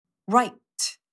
How-to-pronounce-RIGHT-in-American-English_cut_1sec.mp3